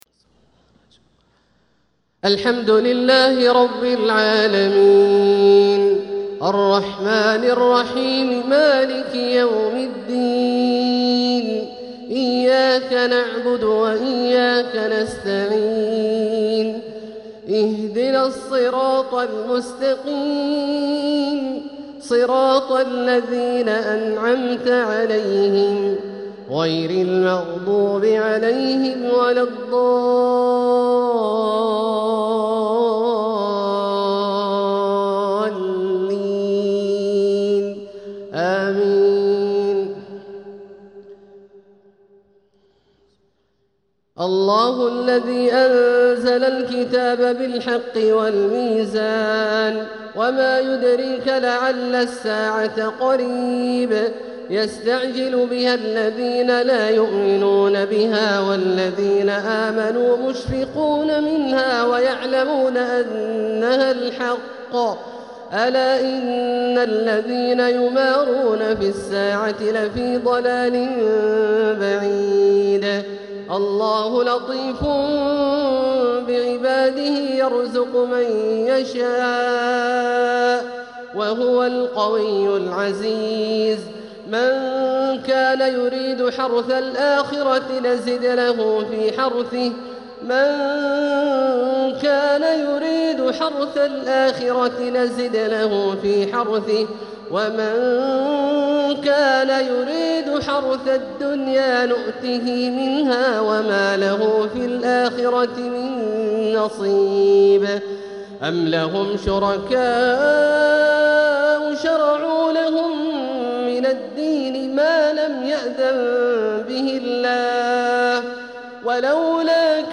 تلاوة من سورة الشورى 17-36 | عشاء الأربعاء 23 ربيع الآخر 1447هـ > ١٤٤٧هـ > الفروض - تلاوات عبدالله الجهني